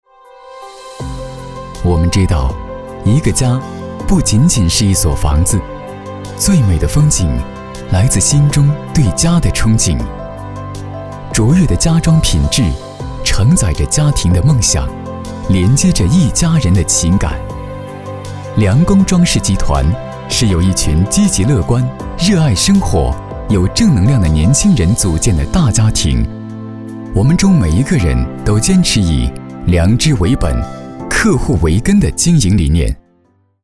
男国184年轻活力时尚配音-新声库配音网
男国184_专题_企业_装饰集团公司_温情.mp3